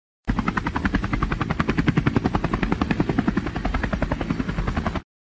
helicopter_se.mp3